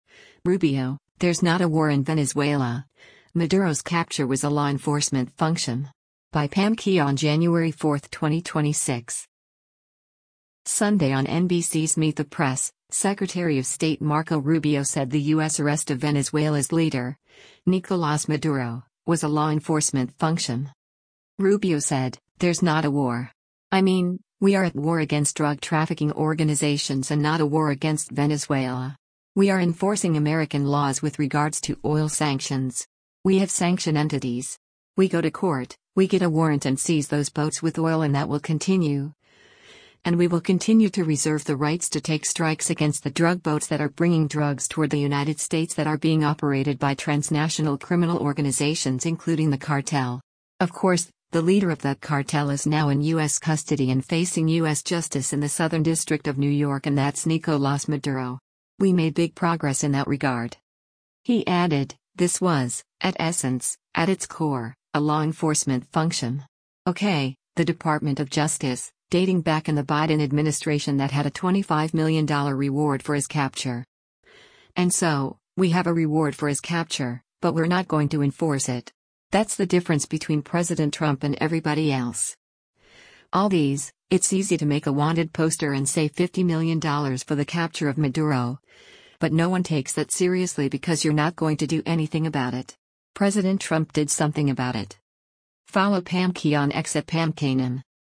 Sunday on NBC’s Meet the Press, Secretary of State Marco Rubio said the U.S. arrest of Venezuela’s leader, Nicolás Maduro, was a “law enforcement function.”